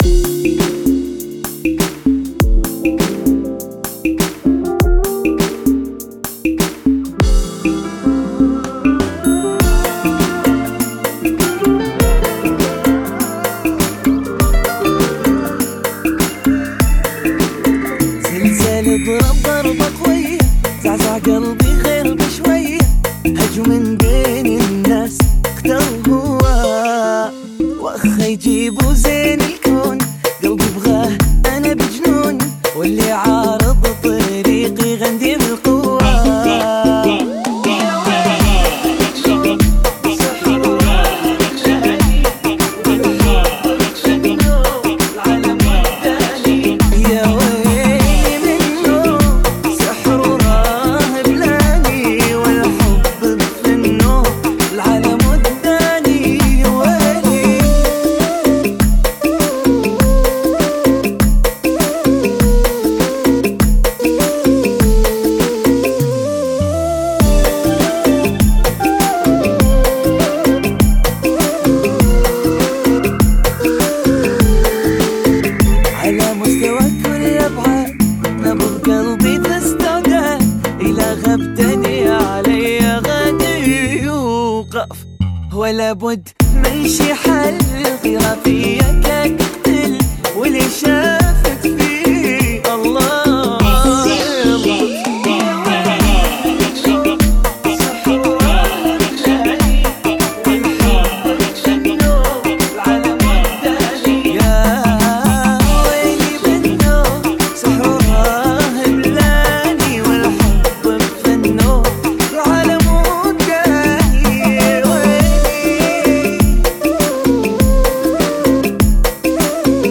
Funky Mix